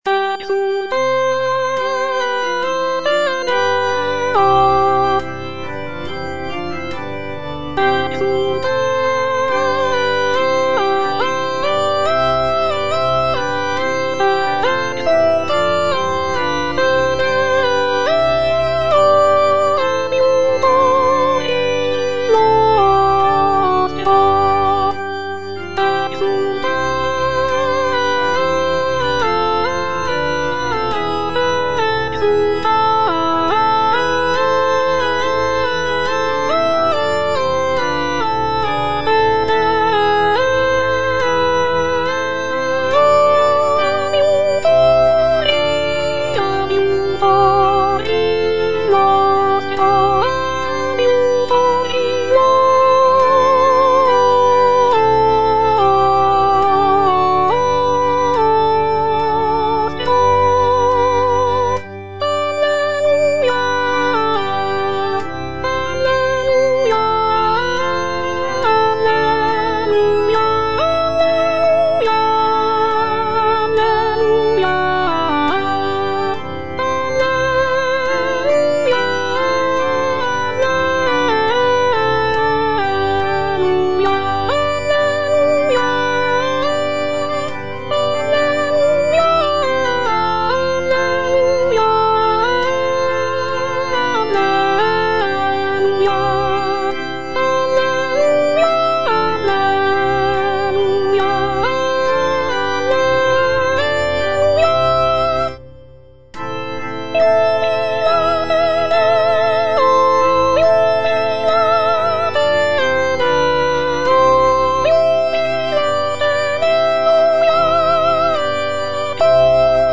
Soprano (Voice with metronome) Ads stop
sacred choral work